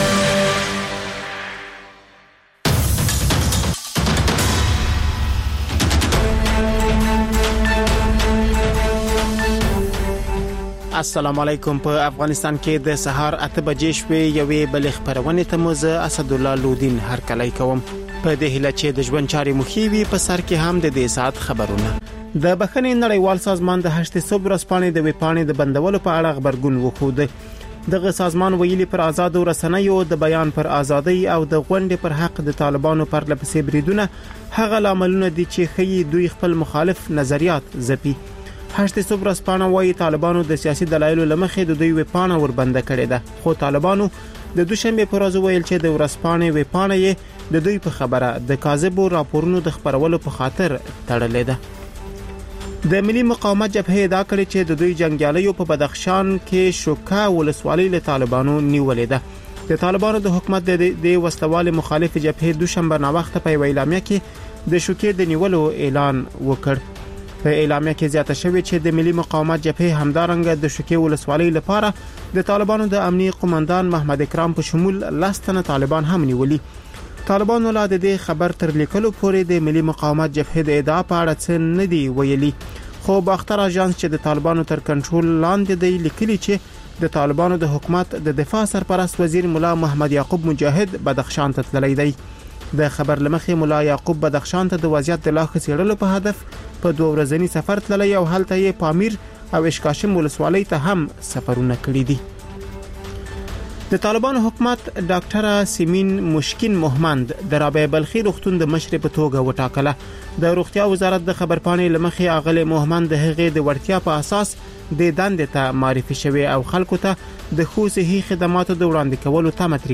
پخش زنده - رادیو آزادی